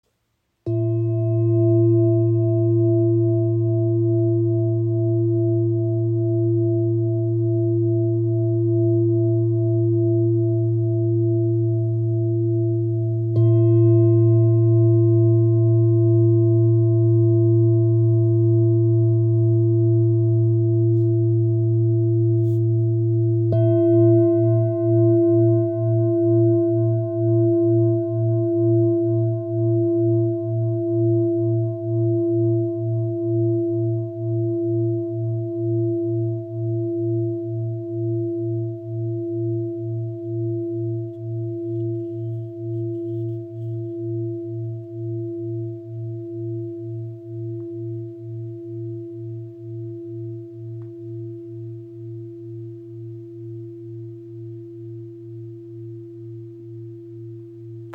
Tibetische Klangschale 24.5 cm – klarer Ton Bb (A#) aus Nepal • Raven Spirit
Klarer, tiefer Ton Bb (A#) – ideal für Klangarbeit, Meditation und Entspannung.
Klangbeispiel
Ihr obertonreicher Klang im Ton Bb (A#) ist klar und erdend.